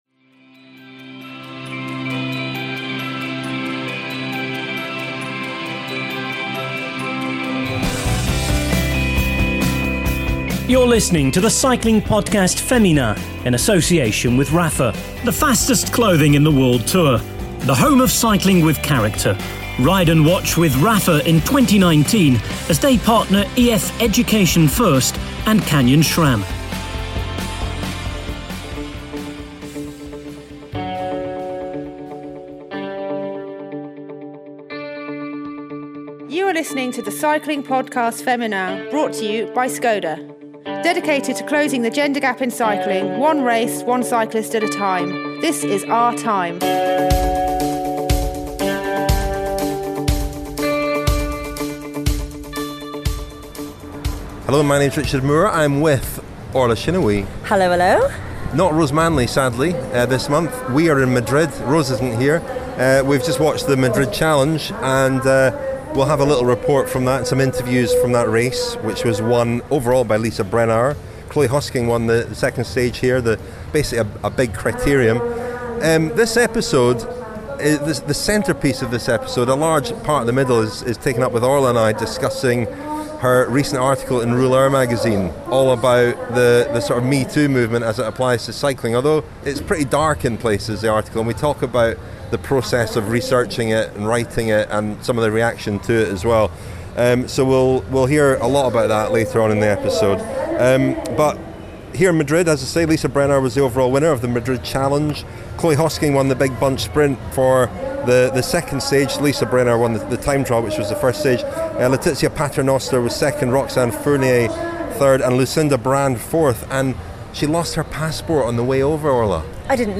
The latest episode of The Cycling Podcast Féminin comes from Madrid where La Madrid Challenge was won by Lisa Brennauer after Chloe Hosking took the second and final stage in the city centre. We hear from Brennauer and Hosking and also Christine Majerus, the recent winner of the Boels Ladies Tour.